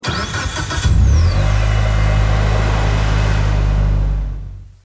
Home gmod sound vehicles tdmcars silvia
enginestart.mp3